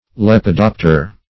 Meaning of lepidopter. lepidopter synonyms, pronunciation, spelling and more from Free Dictionary.
Search Result for " lepidopter" : The Collaborative International Dictionary of English v.0.48: Lepidopter \Lep`i*dop"ter\ (l[e^]p`[i^]*d[o^]p"t[~e]r), n. [Cf. F. l['e]pidopt[`e]re.]